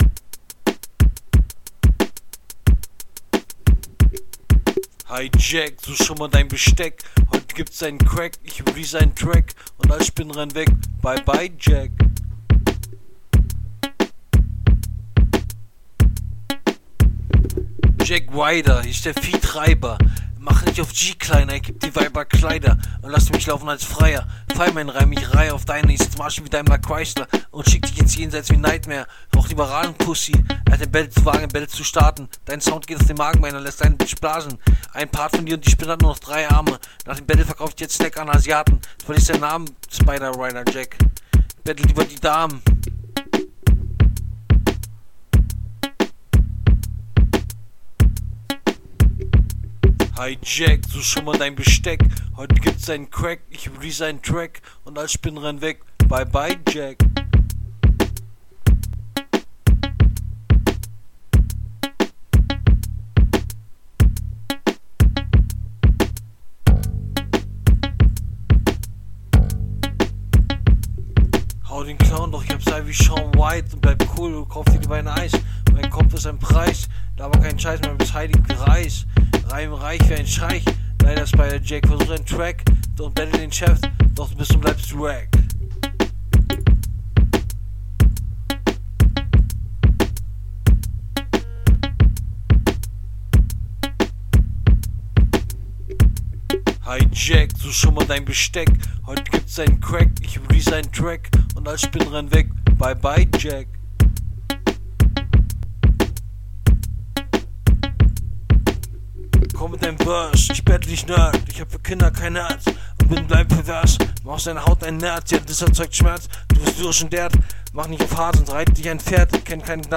Beat sehr einfach gehalten. Stimme und Flow extrem ausbaufähig. Du bist dauerhaft offbeat.